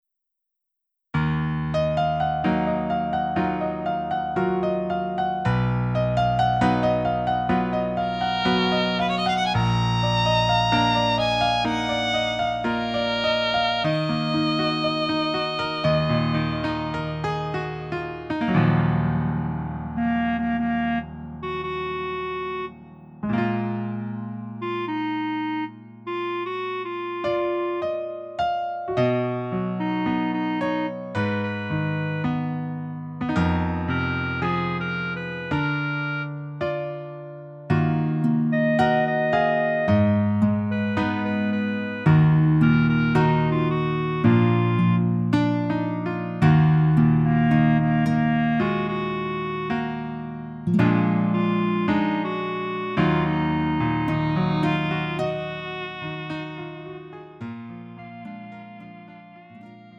음정 원키 3:39
장르 가요 구분 Lite MR